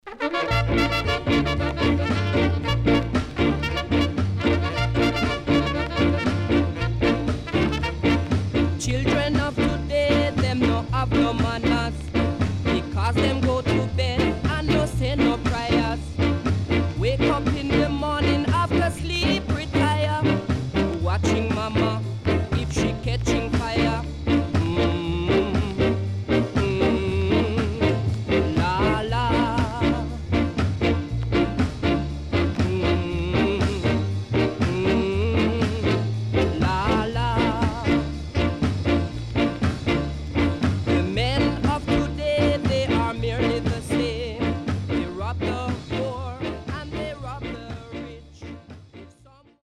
HOME > SKA
Killer Ska Inst & Nice Vocal
SIDE A:所々チリノイズがあり、少しプチパチノイズ入ります。